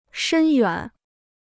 深远 (shēn yuǎn): far-reaching; profound and long-lasting.